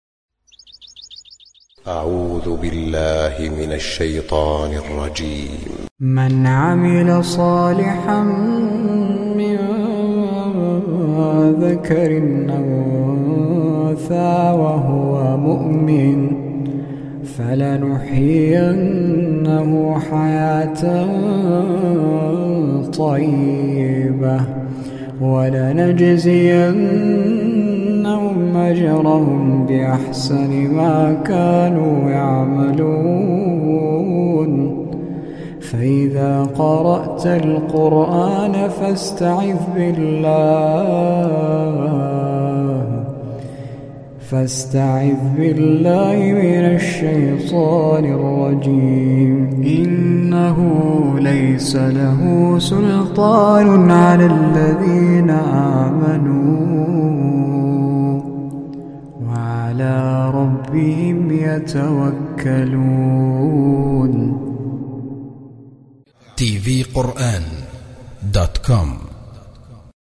إلى جانب ذلك فهو يتمتع بصوت عذب رقيق يجعل صوته تخشع له القلوب وهو صوت لا مشابه له بين القراء.